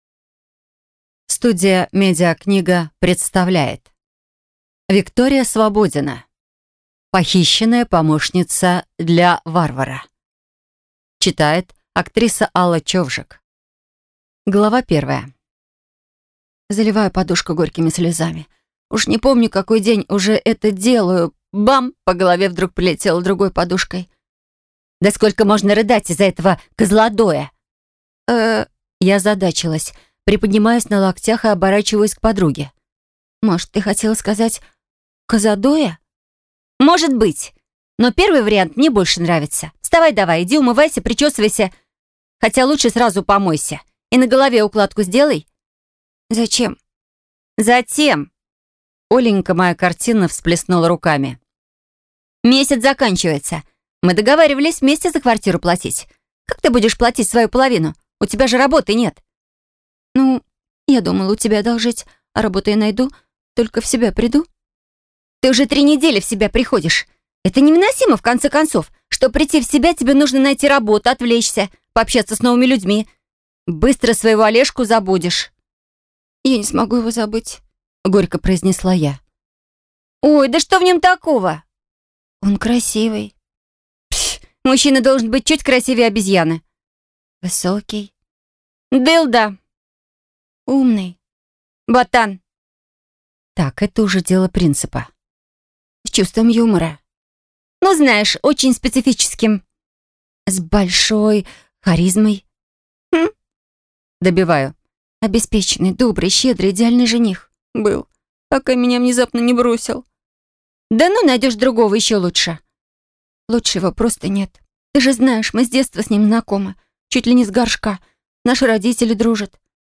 Аудиокнига Похищенная помощница для варвара | Библиотека аудиокниг